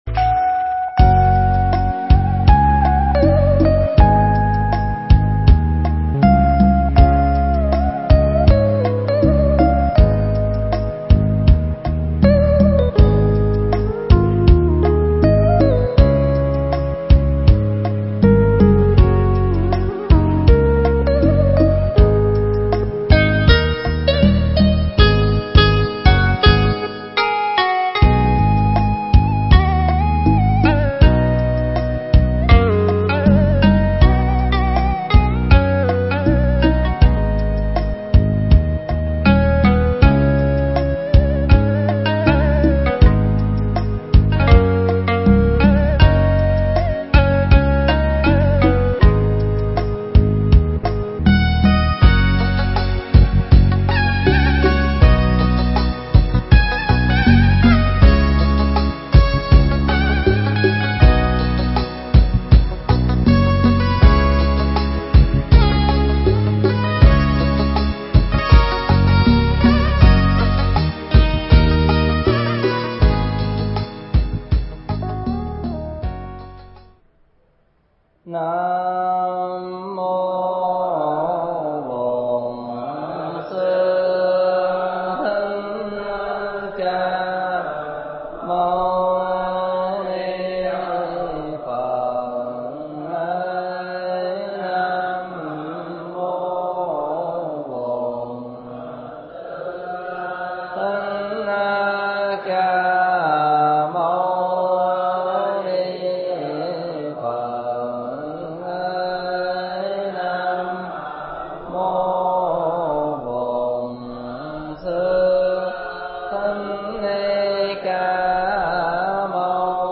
Mp3 Thuyết Pháp Kinh Di Giáo 13
giảng trong mùa an cư tại chỗ 2016 tại Tu Viện Tường Vân